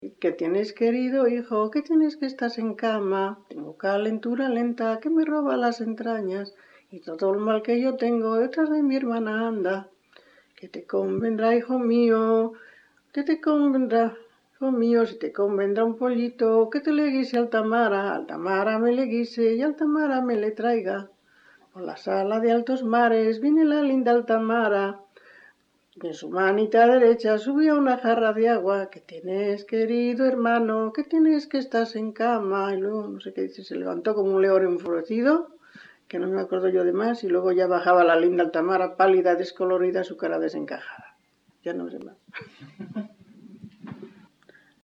Grabación realizada en La Overuela (Valladolid), en 1977.
Género / forma: Canciones populares-Valladolid (Provincia) Icono con lupa